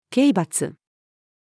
刑罰-female.mp3